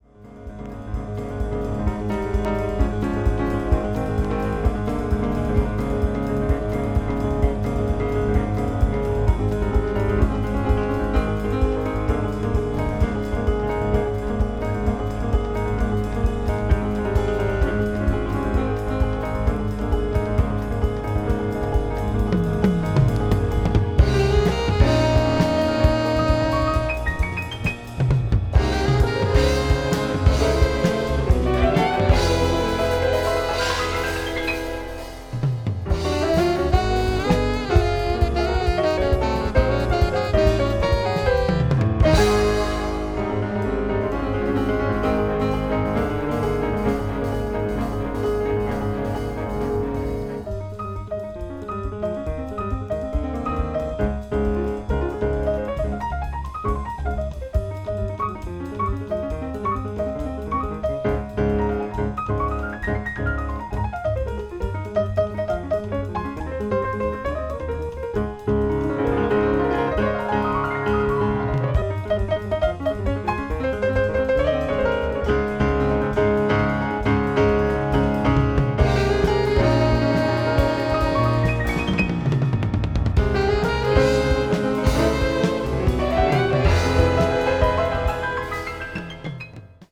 media : EX/EX(わずかにチリノイズが入る箇所あり)
contemporary jazz   crossover   fusion   soul jazz